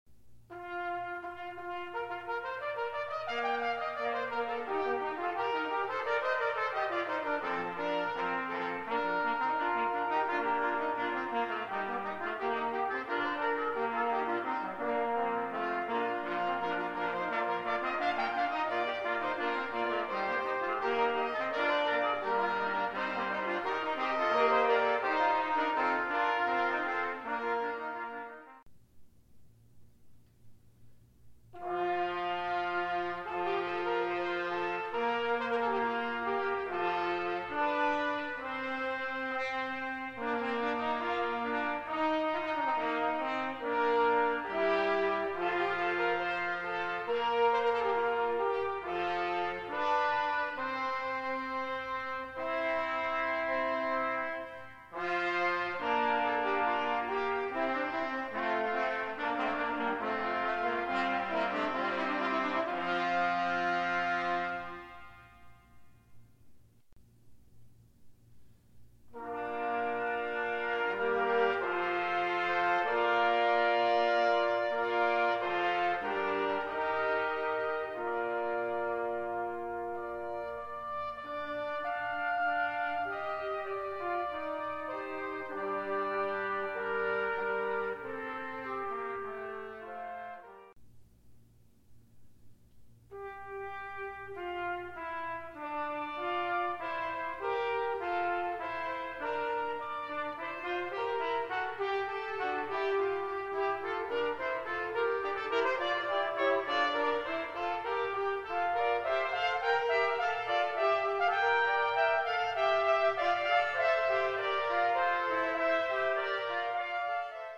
Multiple Trumpets
early Baroque works arranged for four B-flat trumpets